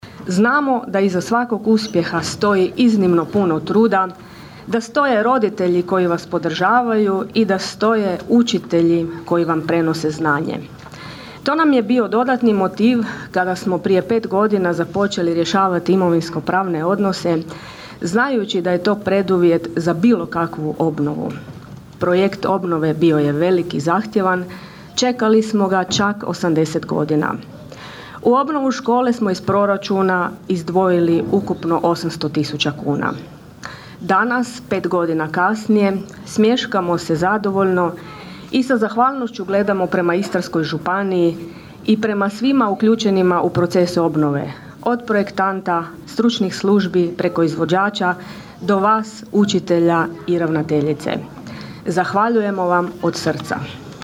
Raška općinska načelnica Glorija Paliska ponosna je, kako je rekla na izuzetne rezultate koje postižu učenici škole: (